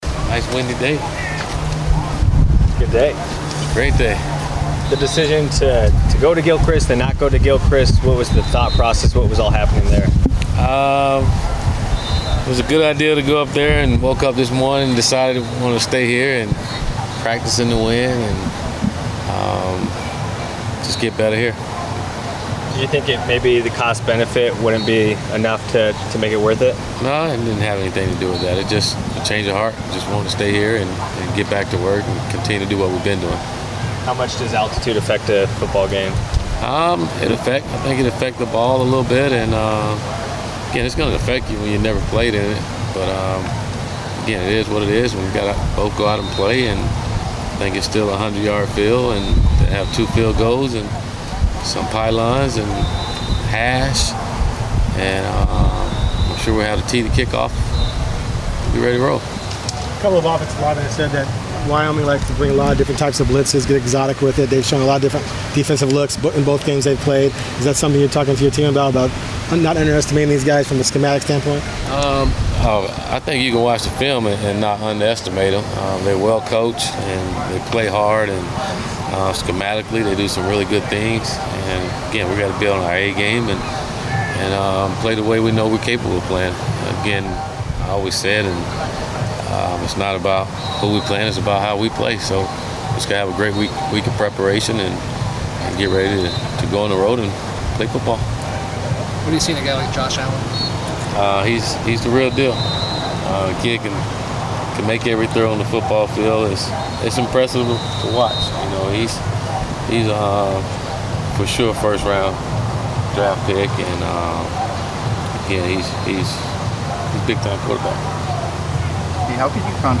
Willie Taggart Media Session 9-12-17